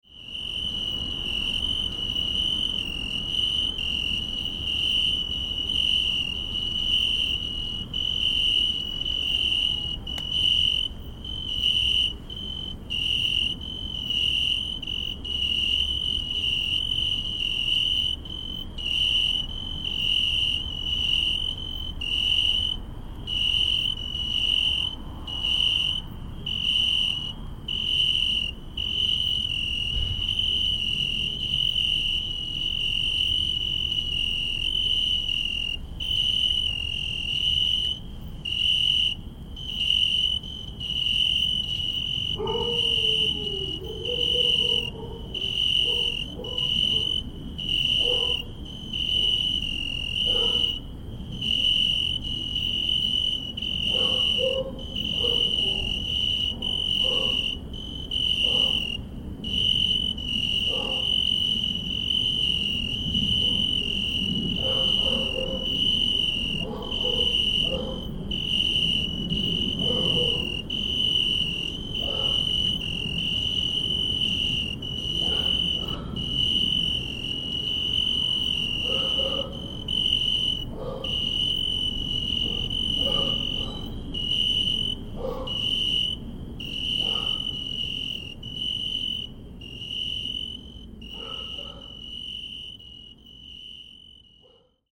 Summer soundscape of Židlochovice